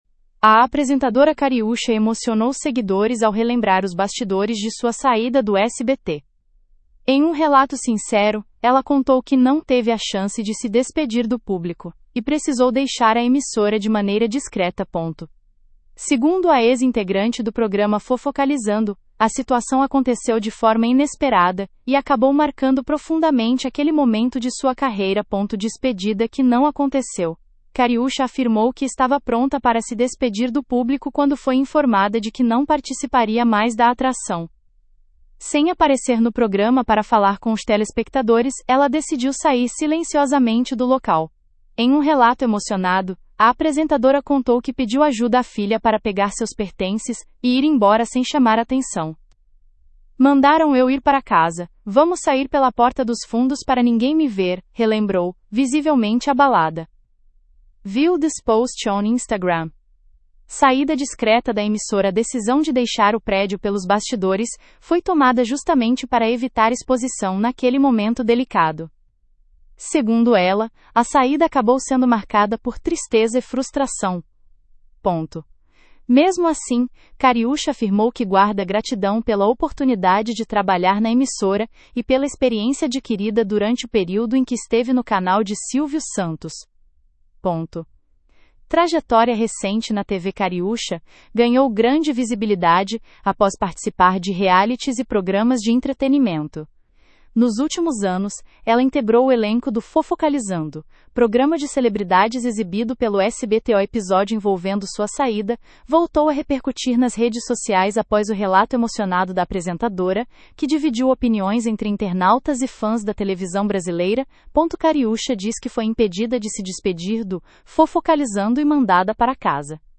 Cariúcha chora ao relembrar saída do SBT e revela: “Tive que sair pela porta dos fundos”